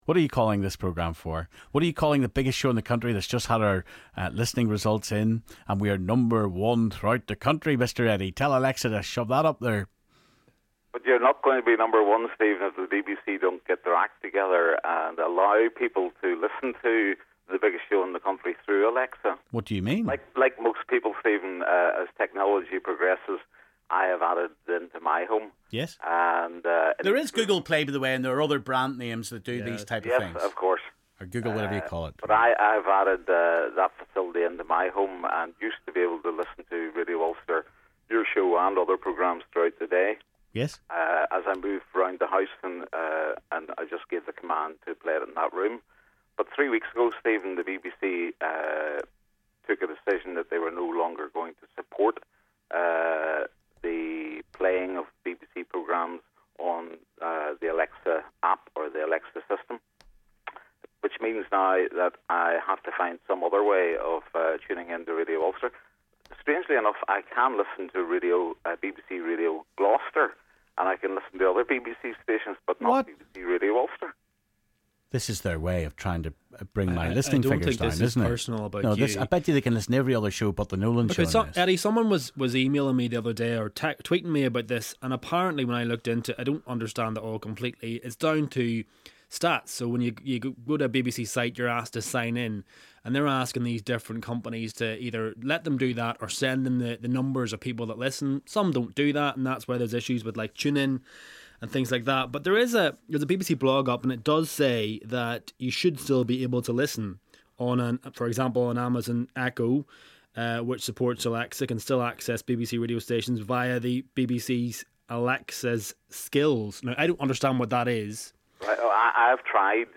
A caller tells Stephen that their smart speaker won’t play The Biggest Show in the County.